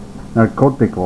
Click on the Spanish word to hear it pronounced.